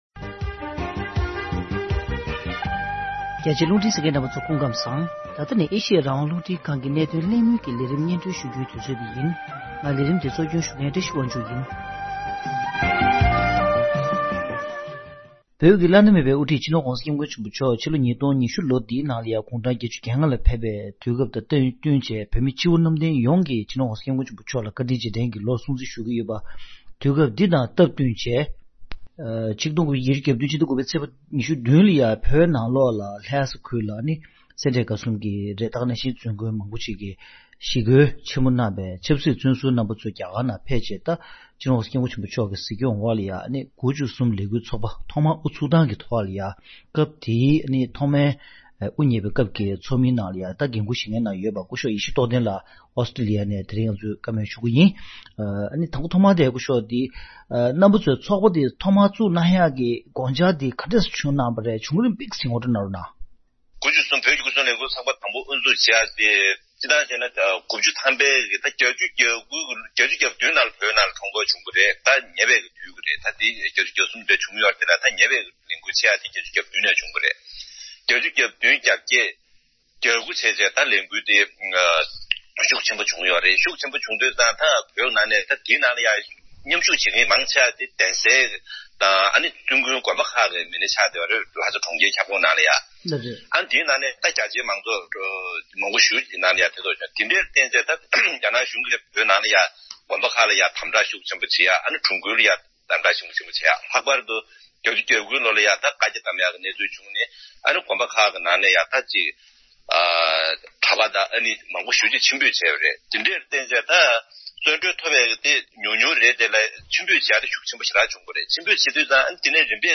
གནད་དོན་གླེང་མོལ